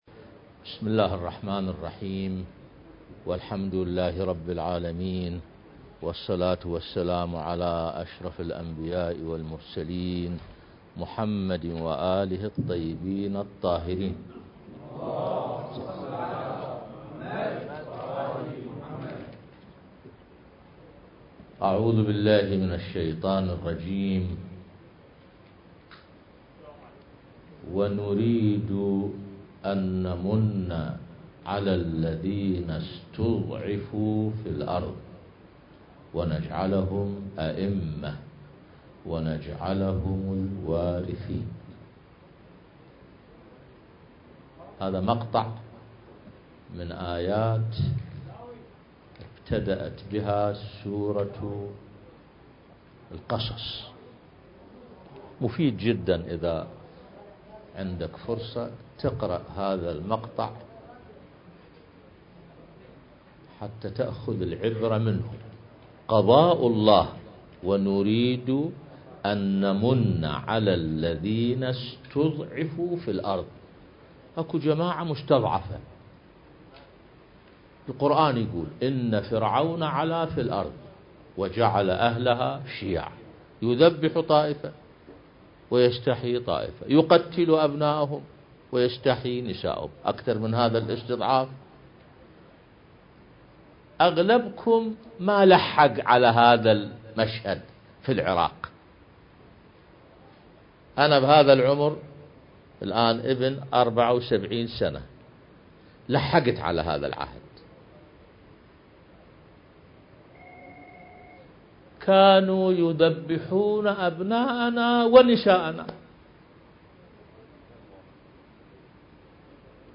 ضمن برنامج إحياء ليلة النصف من شعبان المكان: كربلاء المقدسة